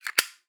zippo_close_02.wav